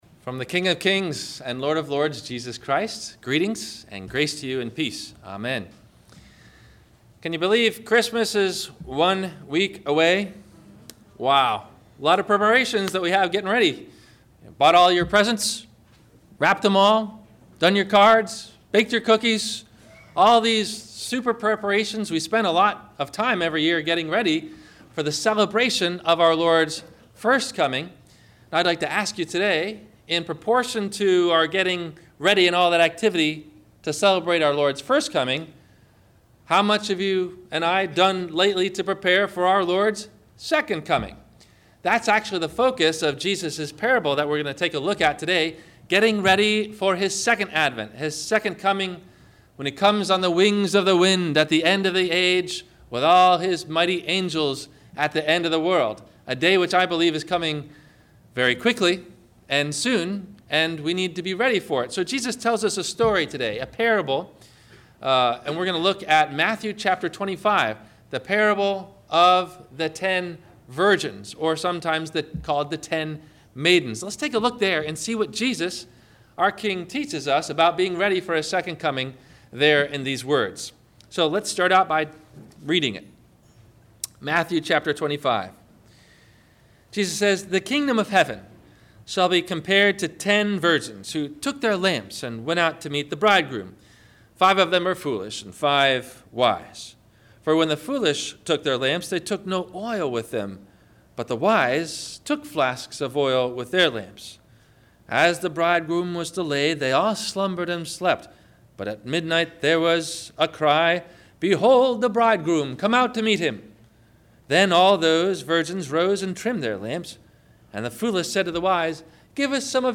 Questions to think about before you hear the Sermon: